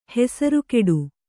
♪ hesaru keḍu